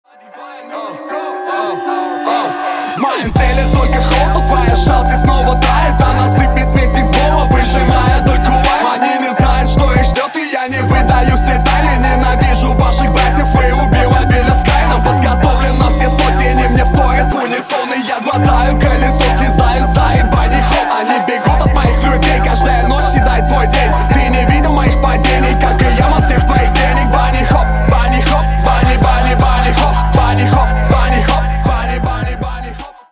• Качество: 320, Stereo
ритмичные
русский рэп